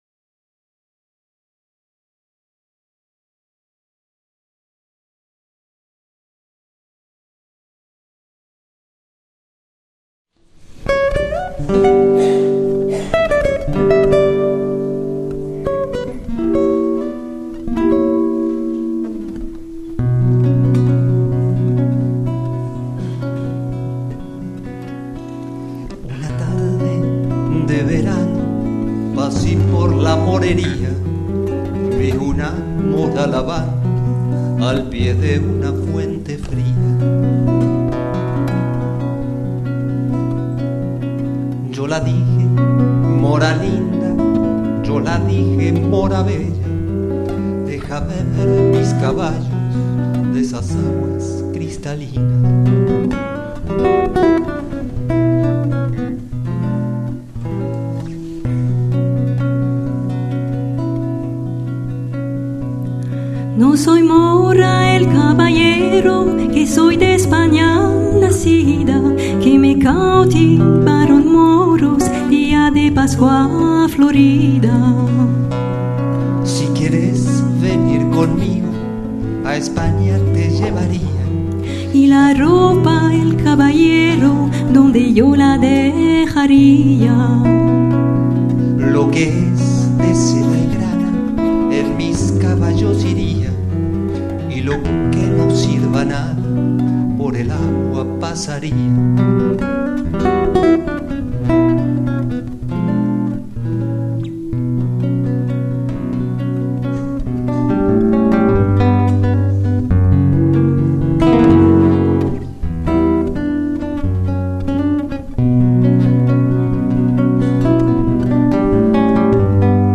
Schauen Sie VideoAusschnitte aus der erste Ausgabe des Galakonzerts des Europäischen Instituts für Jüdische Musik, “Jüdische Musik in all ihren Zuständen”, das am Sonntag, den 8. November 2015, um 17.30 Uhr, im Adyar Theater vor einem begeisterten Publikum von fast 350 Zuschauern, statt.
Traditionelle jüdisch-spanische Lieder (Marokko, Türkei…)
Gesang
Gitarre